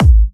VEC3 Bassdrums Trance 43.wav